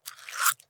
Matches 02.wav